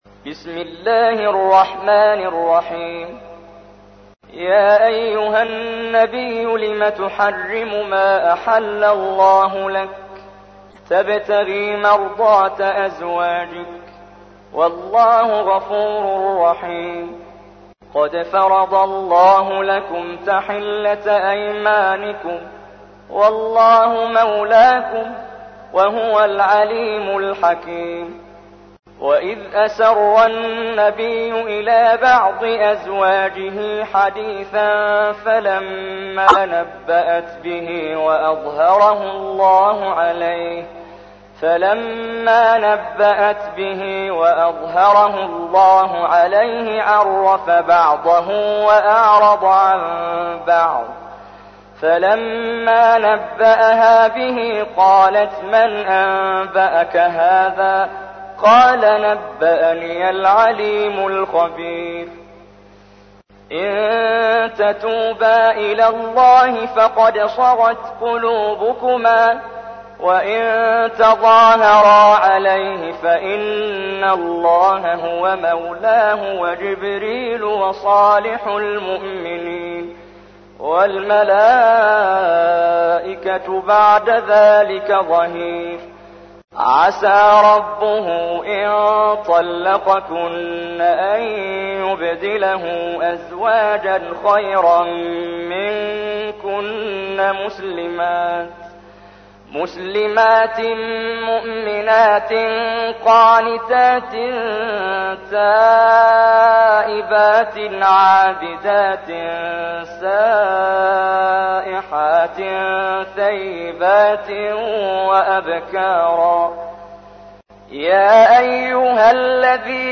تحميل : 66. سورة التحريم / القارئ محمد جبريل / القرآن الكريم / موقع يا حسين